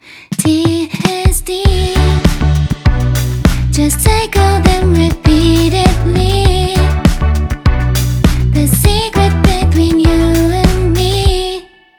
さてそれでは、ここから怒涛の視聴タイムです!! 4コードのループで、TDSの違いを感じてみましょう。今回は単一のメロディを使い回して、さまざまなコード進行をあてていくことにします。
全く同一のメロディが、コード進行次第で異なった雰囲気になるというところをぜひ体感していただきたいです。
同じ機能順行でも、頭に2回連続でT機能のコードを置くとまた緊張-弛緩の流れ方を変えることができます。
特に1-6-2-5は古きよきジャズで定番とされている進行です。